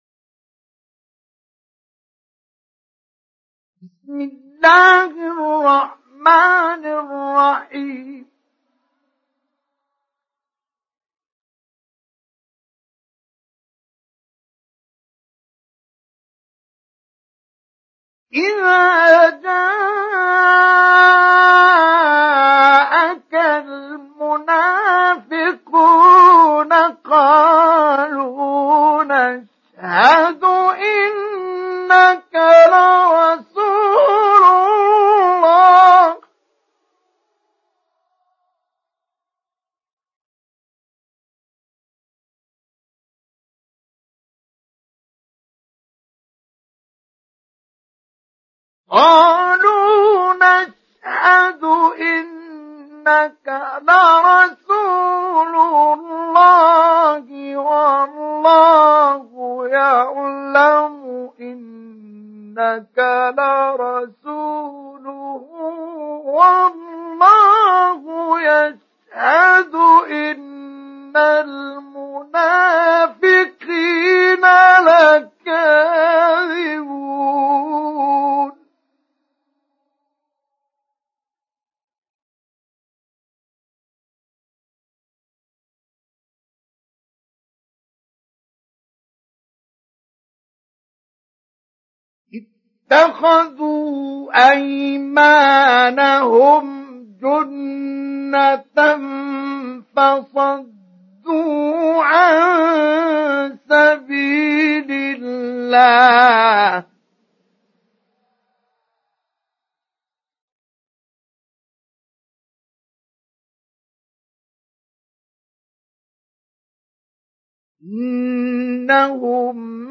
سُورَةُ المُنَافِقُونَ بصوت الشيخ مصطفى اسماعيل